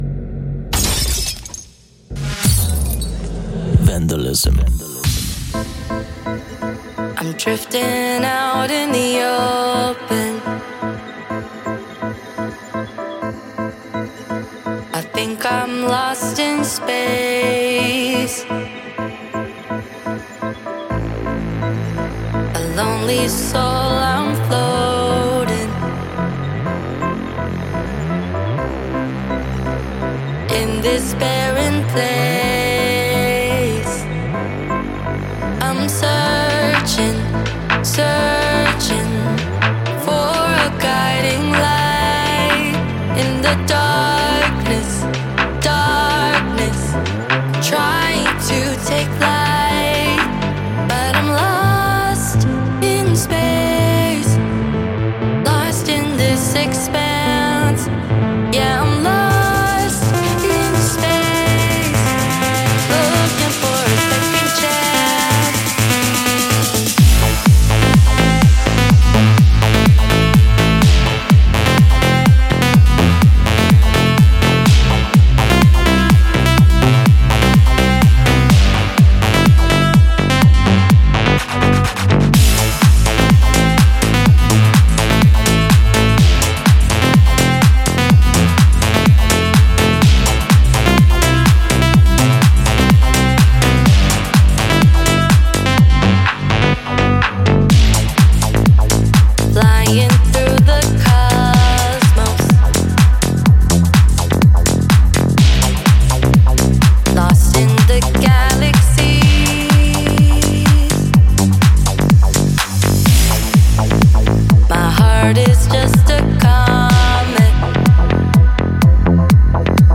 为您带来深沉悦耳的男女声清唱